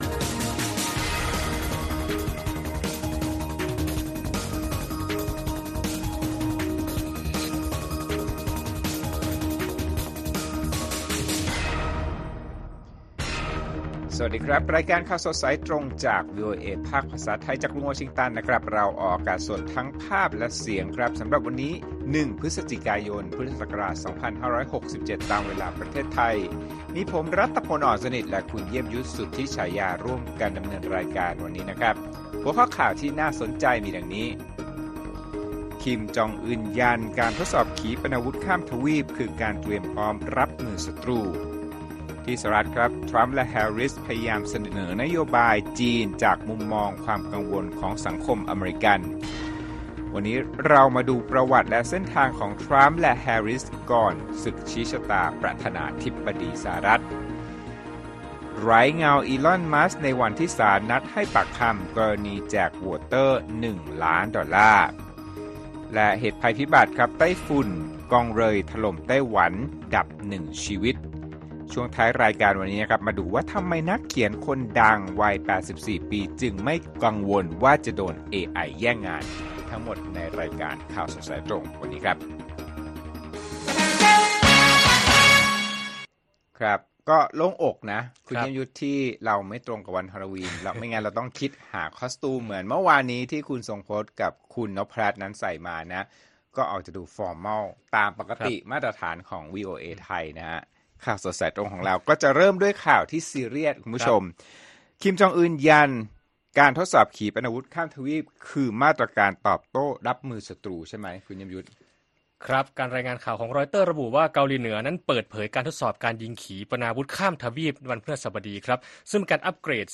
ข่าวสดสายตรงจากวีโอเอไทย วันศุกร์ ที่ 1 พฤศจิกายน 2567